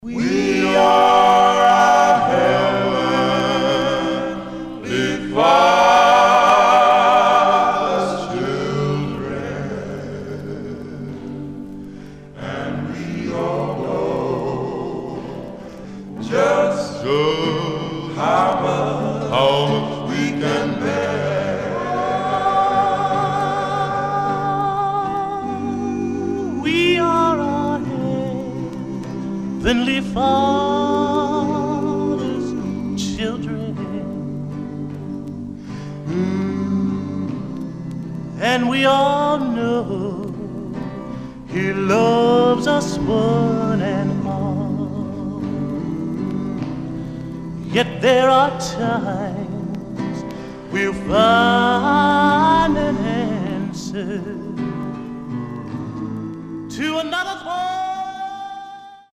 Stereo/mono Mono
Male Black Group Condition